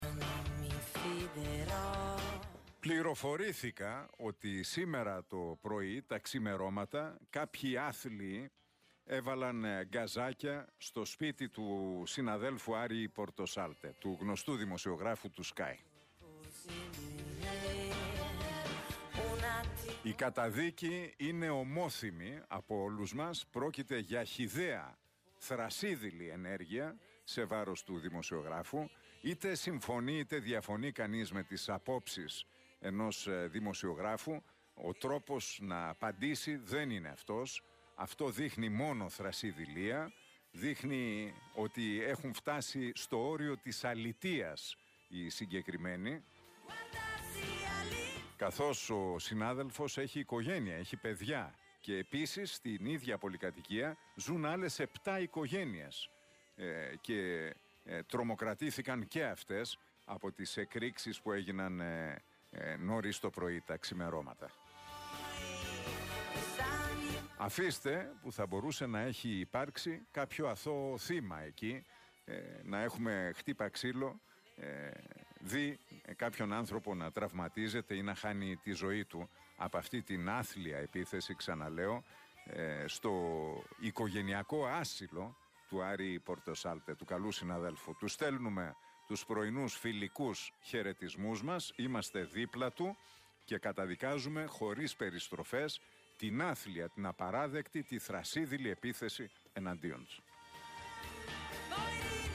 “Πληροφορήθηκα ότι σήμερα το πρωί κάποιοι άθλιοι έβαλαν γκαζάκια στο σπίτι του συναδέλφου, Άρη Πορτοσάλτε, του γνωστού δημοσιογράφου του ΣΚΑΪ. Η καταδίκη είναι ομόθυμη” σχολίασε μέσα από την εκπομπή του στον Realfm 97,8 ο Νίκος Χατζηνικολάου.